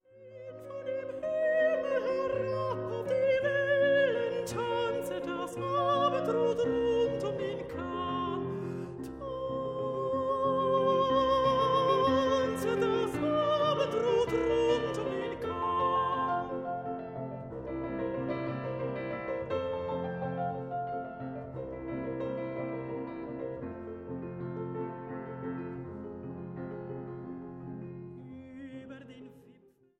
Contratenor
Piano